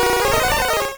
Cri de Crustabri dans Pokémon Rouge et Bleu.